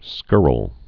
(skûrəl, skŭr-)